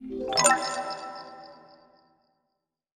SFX_GameStart.wav